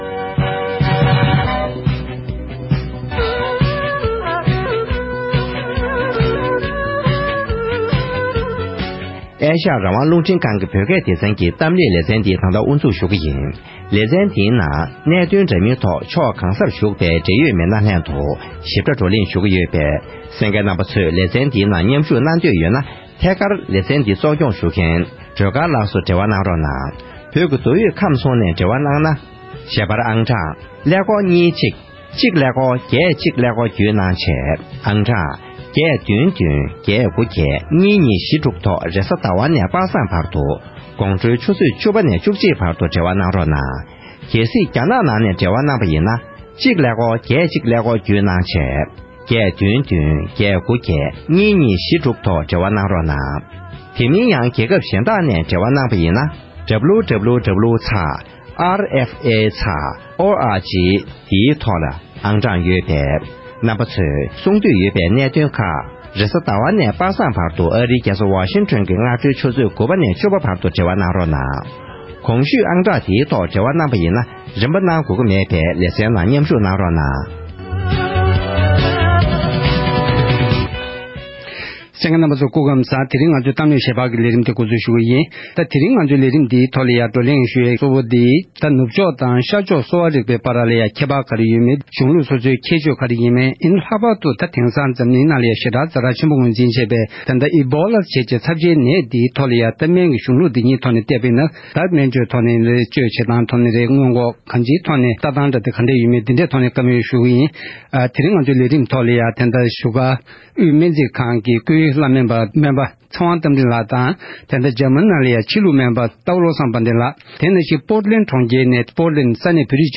བགྲོ་གླེང་ཞུས་པ་ཞིག་གསན་རོགས་གནང་༎